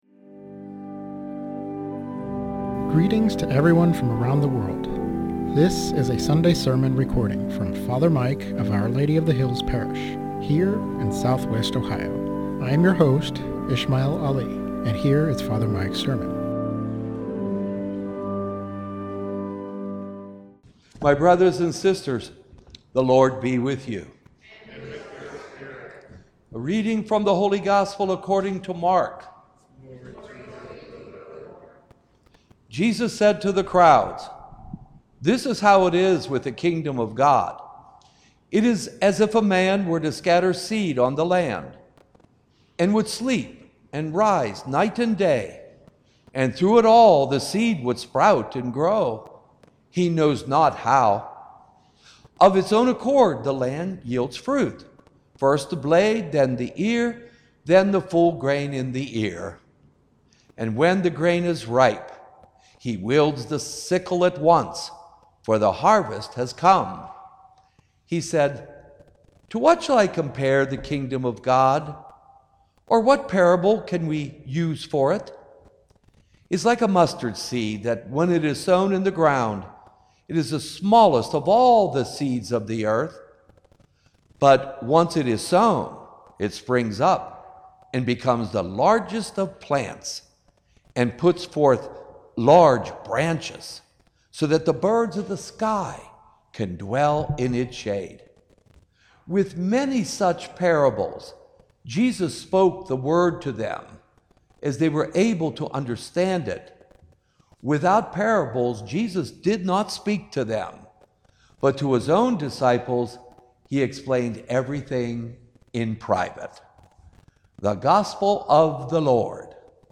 SERMON ON MARK 4:26-34